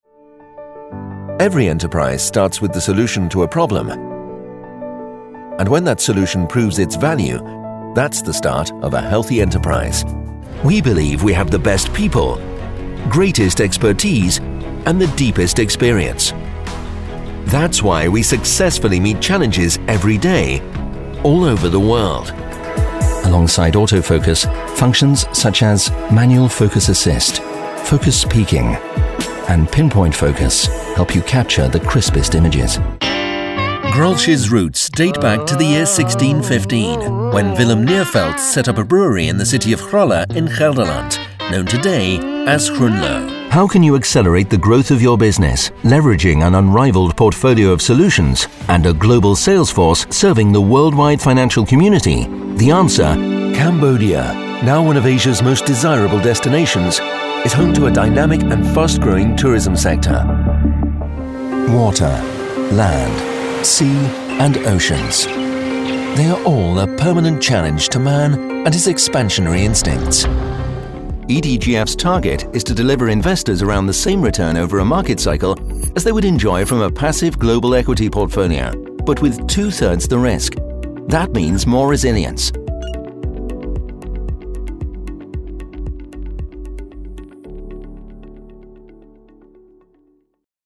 Warm, rich & reassuring British voice, at once approachable and authoritative.
Sprechprobe: Industrie (Muttersprache):